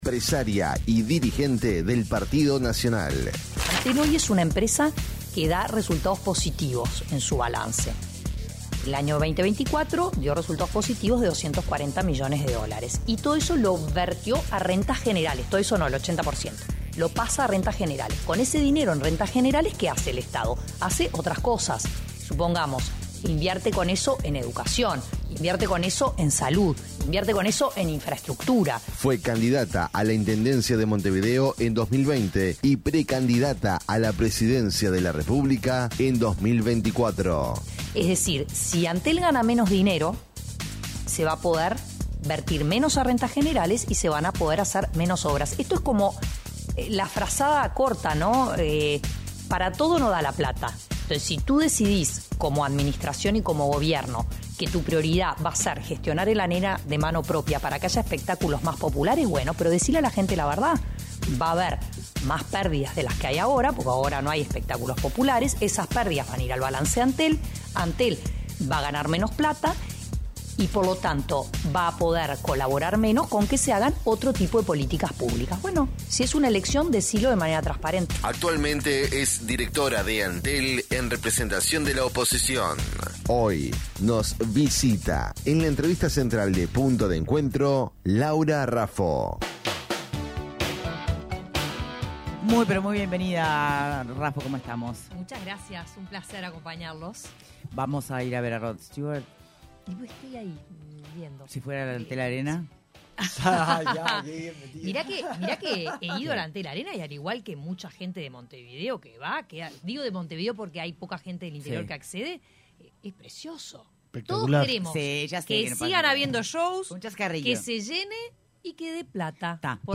La directora de Antel en representación de la oposición, Laura Raffo, dijo en entrevista con Punto de Encuentro que el gerenciamiento del Antel Arena debió licitarse, en vez de que la empresa pública se haga cargo del mismo a través de una subsidiaria, como votaron los representantes del gobierno.